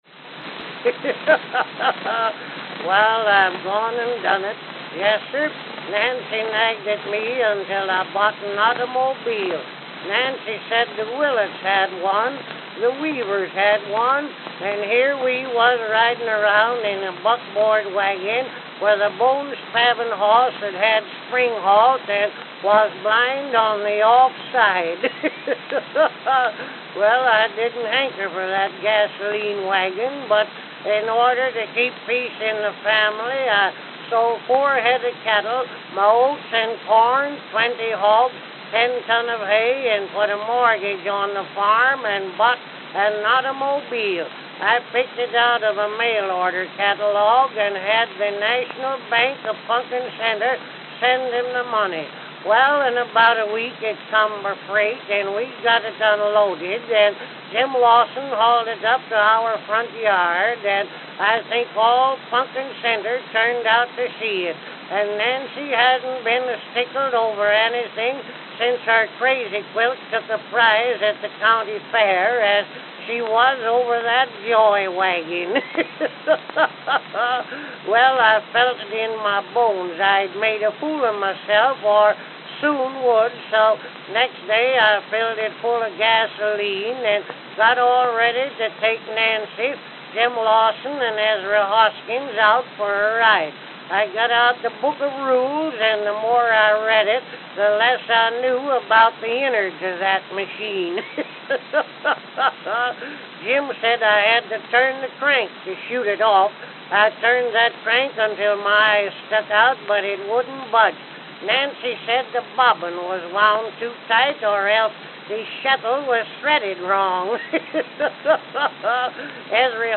Edison Diamond Discs